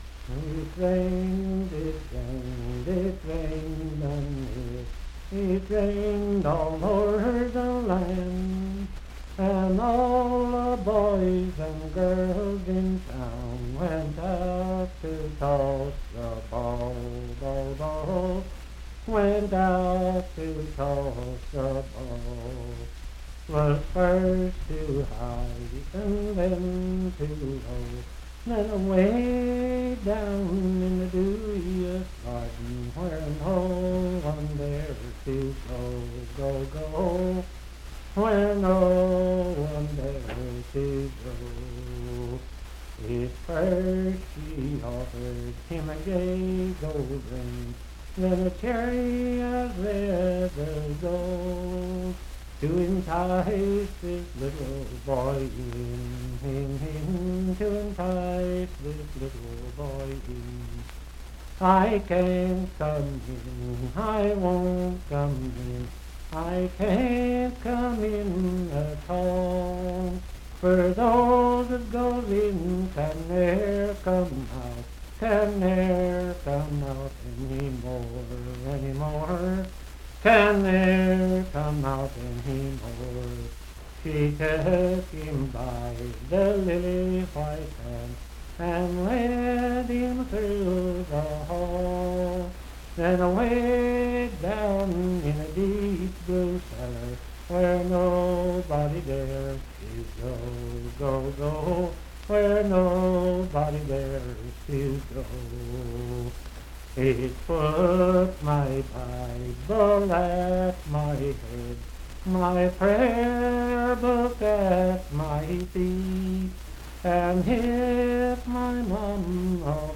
Unaccompanied vocal music
Verse-refrain 6(5).
Voice (sung)